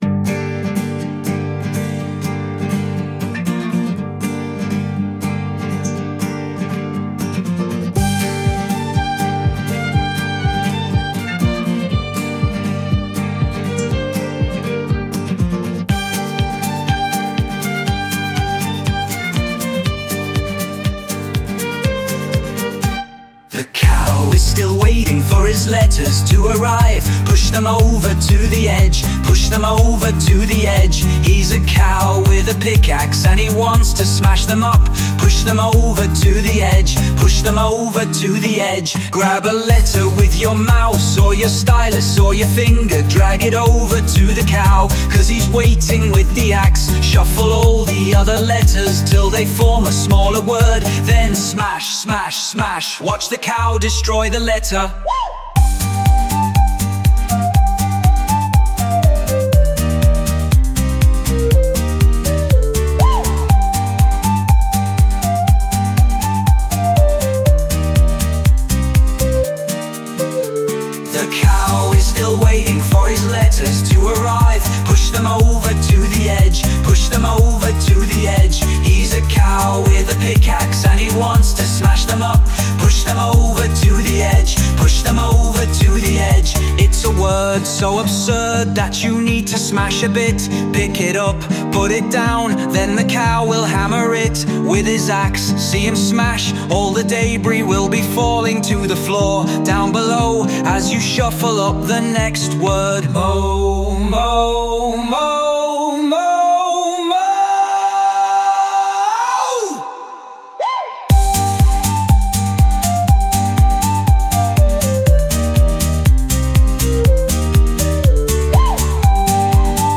Over_to_the_Edge_(Cover)_mp3.mp3